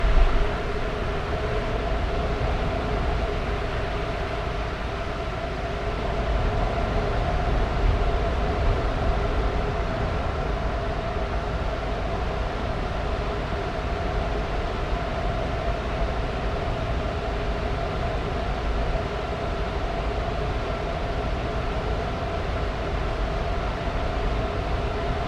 Robotic Soundz " hydrolic hatch bip
我只是希望它可以有点大声。
标签： 背景声 声景 大气 环境 背景 ATMOS 氛围 氛围 ATMO 一般噪声 气氛
声道立体声